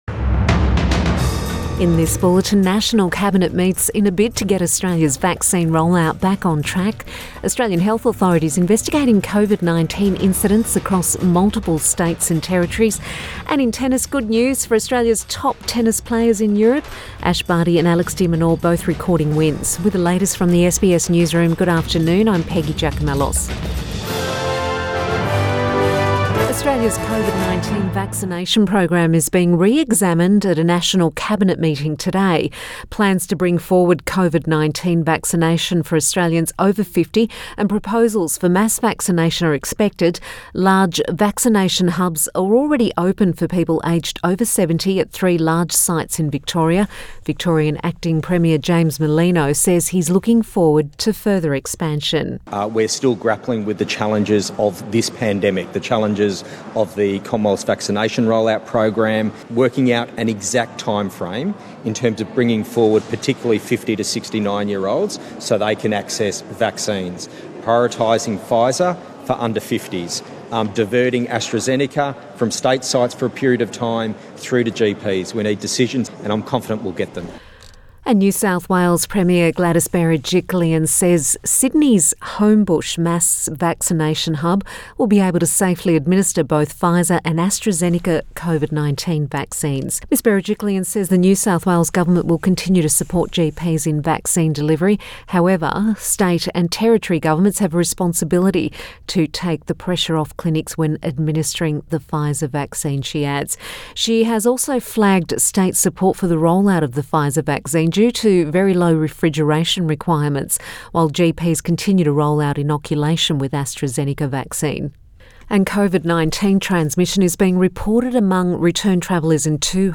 Midday bulletin 22 April 2021